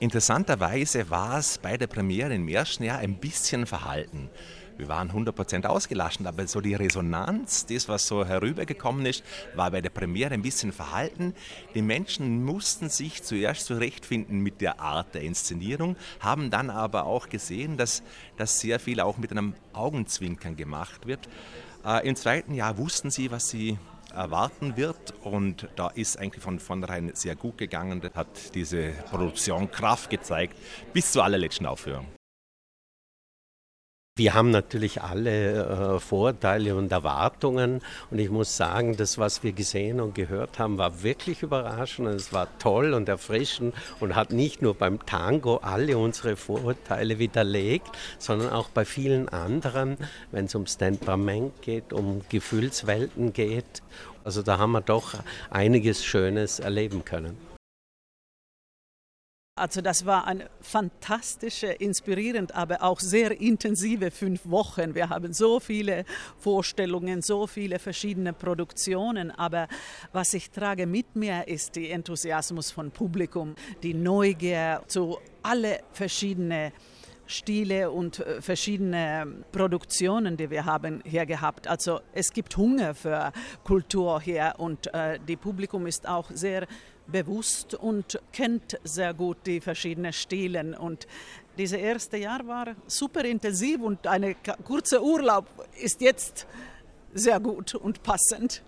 O-Töne Bregenzer Festspiele vorläufige Bilanz 2025 | Feature